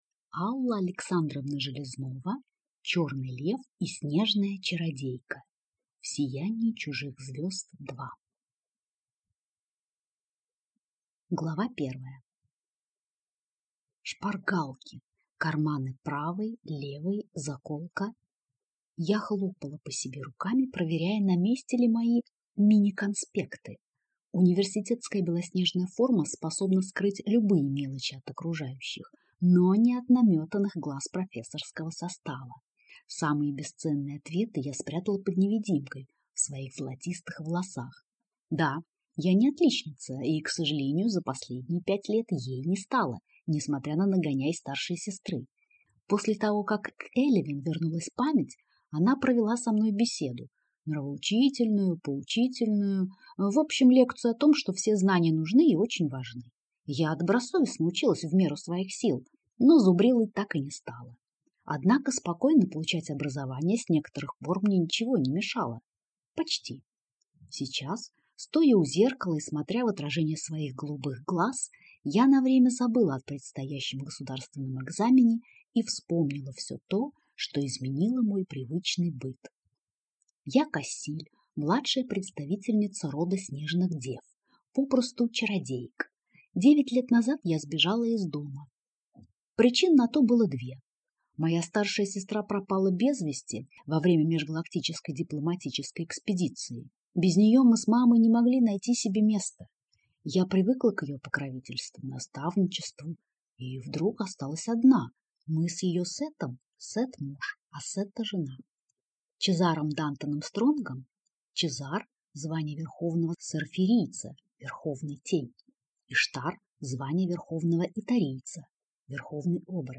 Аудиокнига Черный лев и снежная чародейка | Библиотека аудиокниг